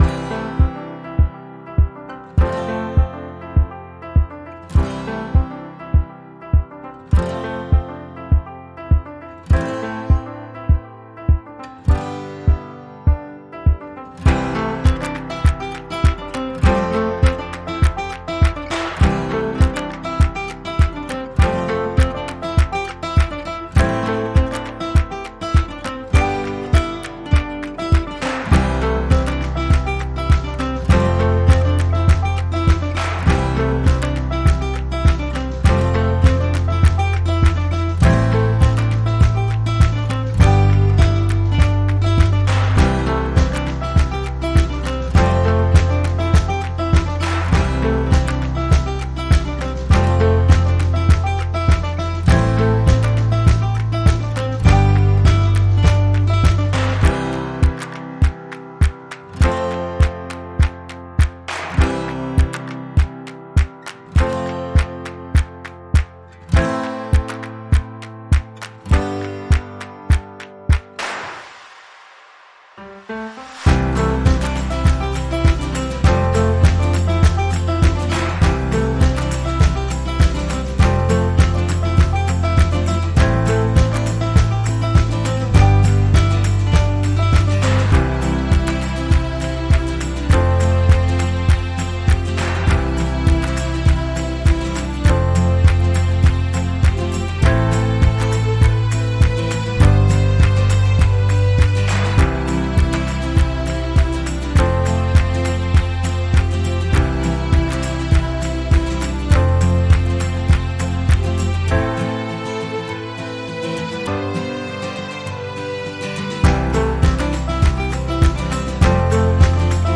Feel good inspirational track